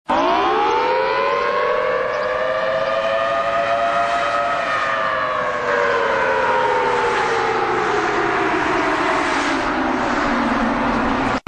Syreny alarmowe w stolicy Kuwejtu.
001_kuwejtsyreny!!s.mp3